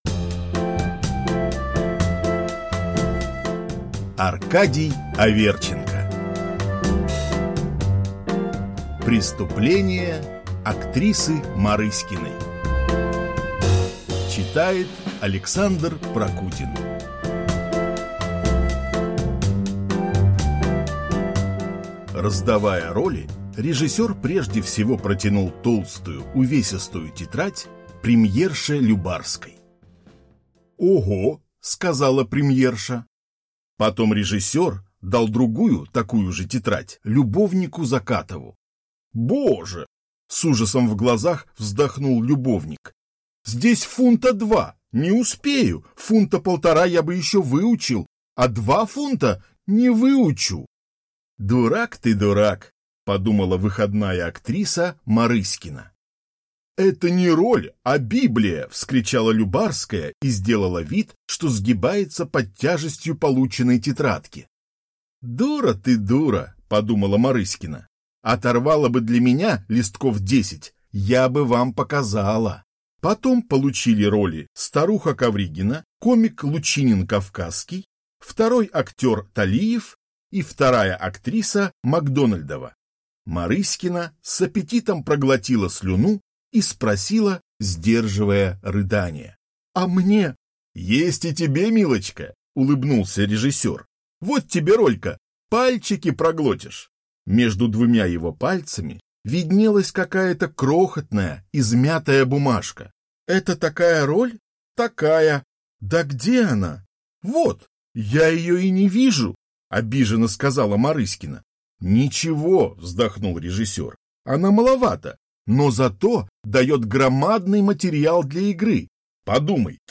Аудиокнига Преступление актрисы Марыськиной | Библиотека аудиокниг